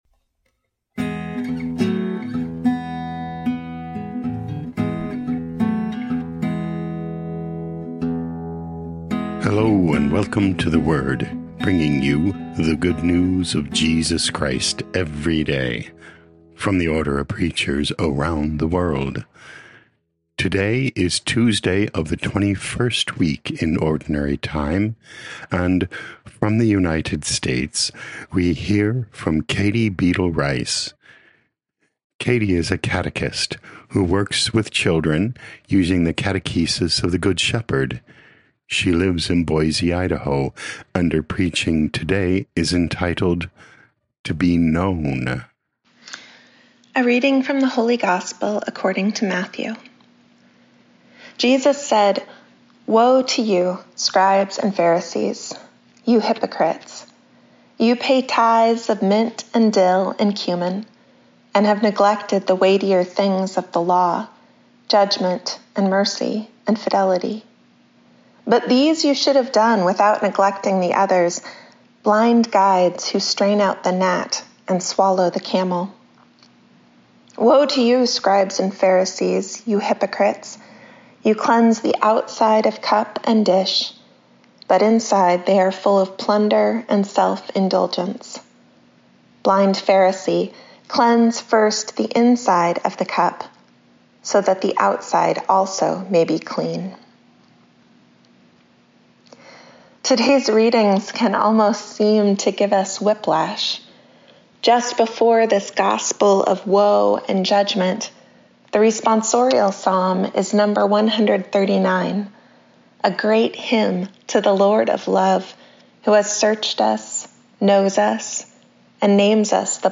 26 Aug 2025 To Be Known Podcast: Play in new window | Download For 26 August 2025, Tuesday of week 21 in Ordinary Time, based on Matthew 23:23-26, sent in from Boise, Idaho, USA.